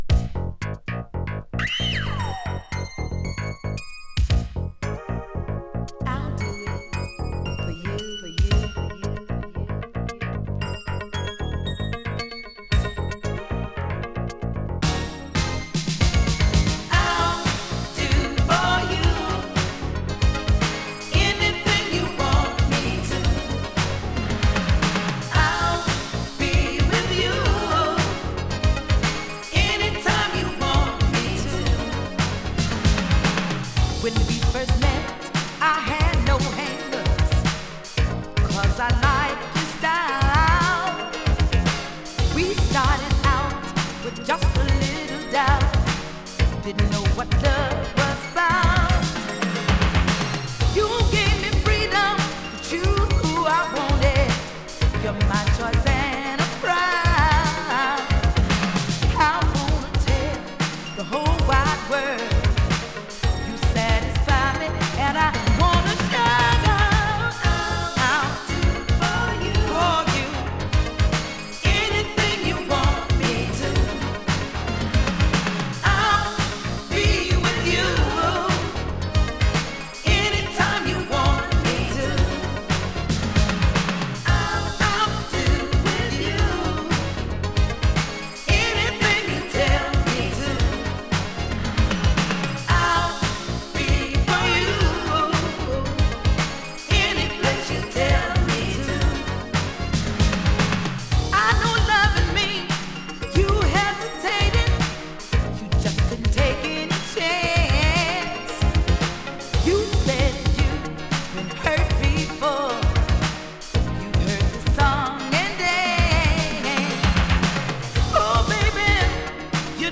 【BOOGIE】 【SOUL】